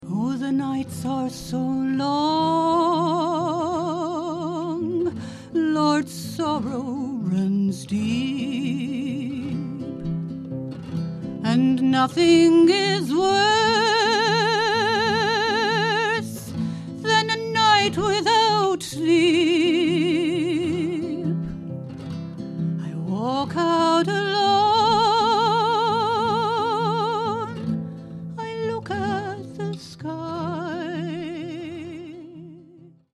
Ashington Folk Club - Singers, Musicians & Poets 01 June 2006